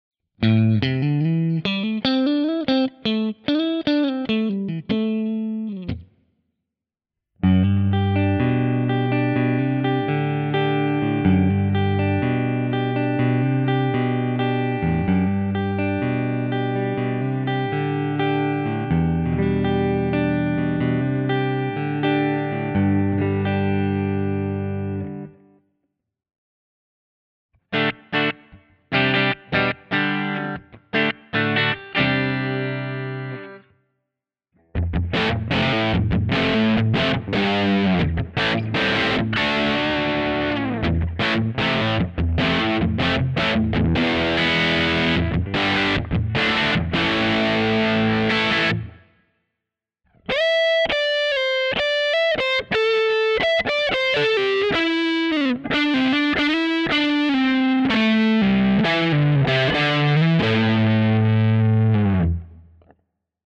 This pack contains 14 Tone Models, Profiles or Captures of a boutique quality 5F1, Tweed Champ style amp, into a vintage Marshall Quad, with 1978 Celestion Blackback (Greenback) speakers.
Simple, pure Class A richness, with the girth and muscle of the quad box. Clean, through crunch, to full gain plus (using an Ibanez EQ10 as a boost), ready to use as is or shape with the ToneX, Quad Cortex or Kemper tone controls.
Tone Samples